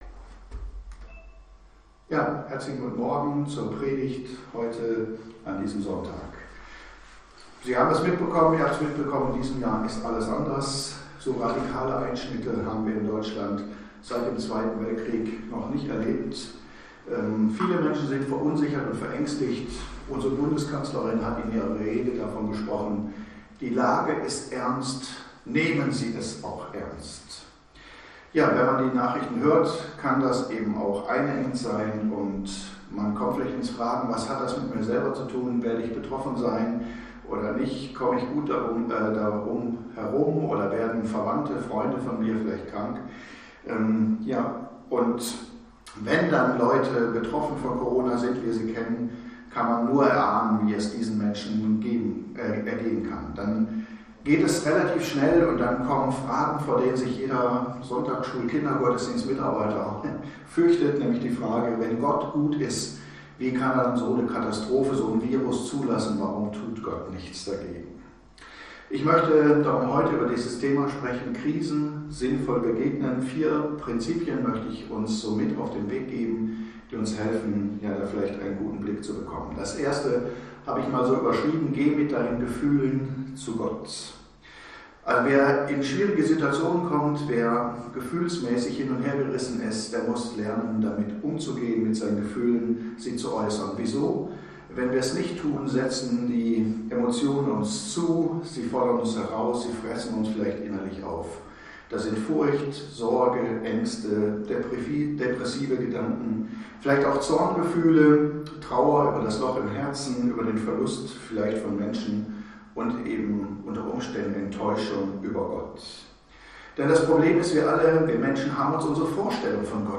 Die Predigt steht als Video und als Audio zur Verfügung, außerdem kann der die Predigt begleitende „Denkzettel“ als pdf heruntergeladen werden.